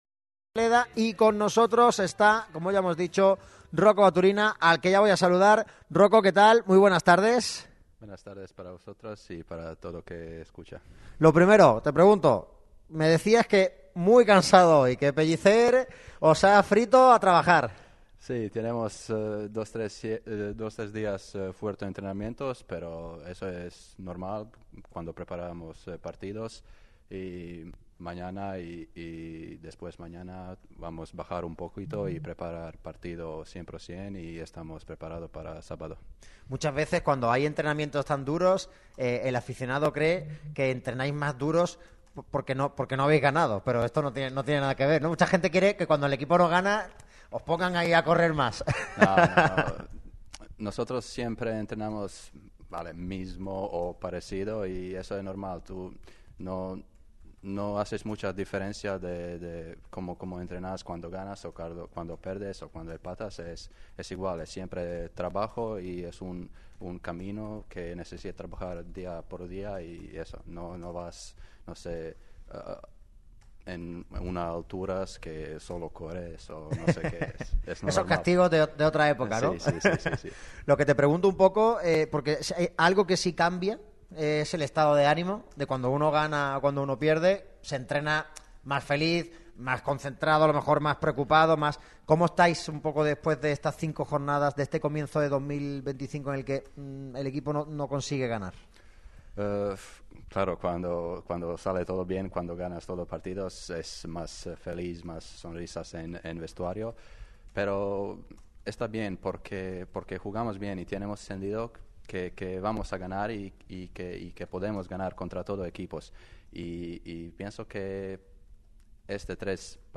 Entrevistón hoy en Radio MARCA Málaga.
Roko Baturina, delantero del Málaga CF, ha pasado por la radio del deporte para ofrecer una de las charlas más interesantes de la temporada.